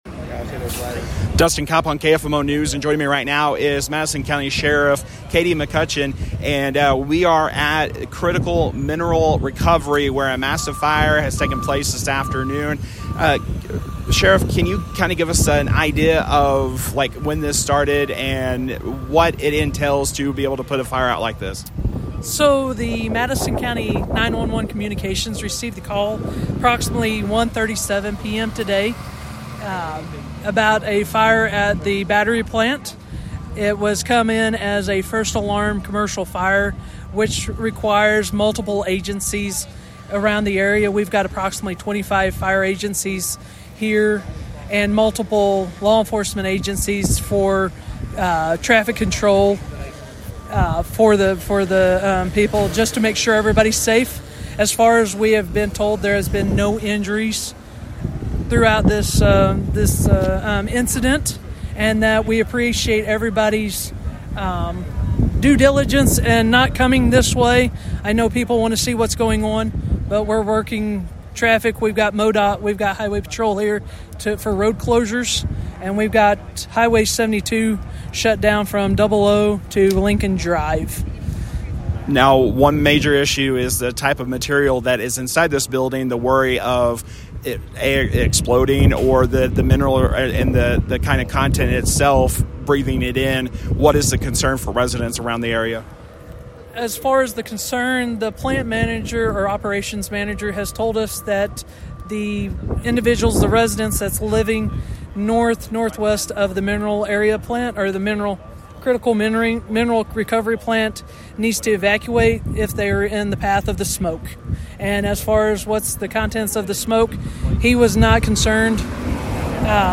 A full interview with Madison County Sheriff Katy McCutcheon can be found below.